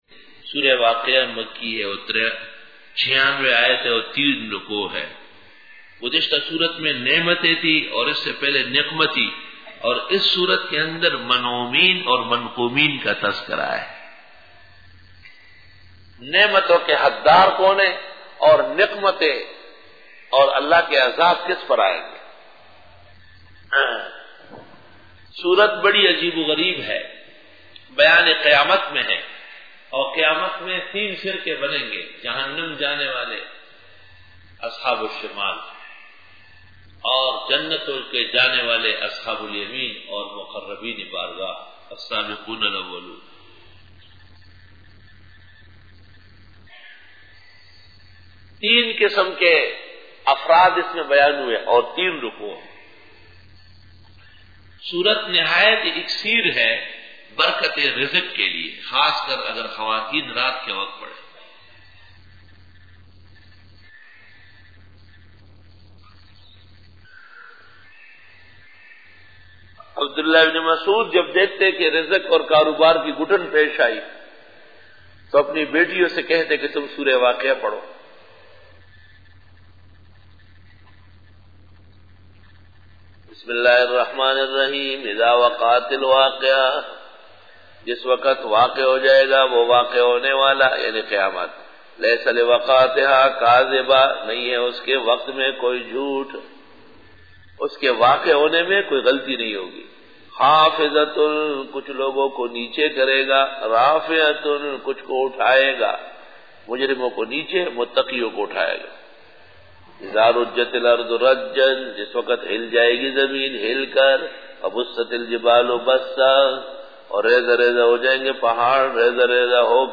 Dora-e-Tafseer 2003